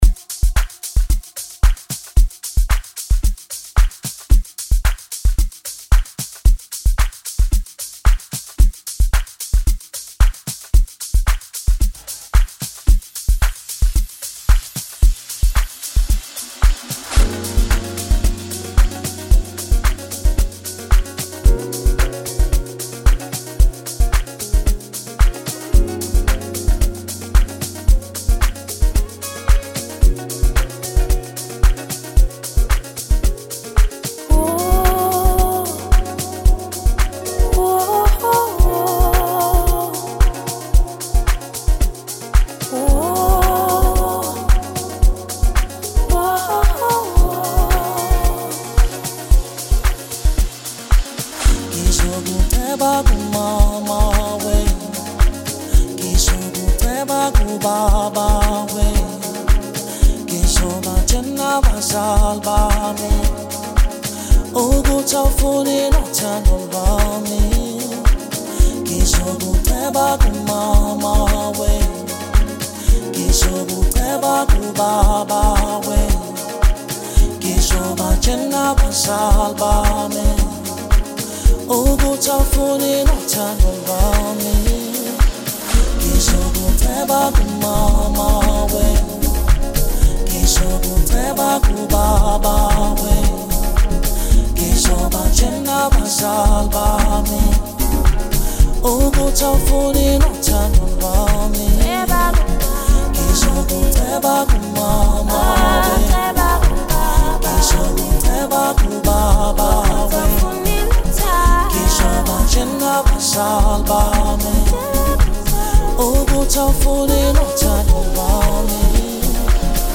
creating a seamless blend of voices.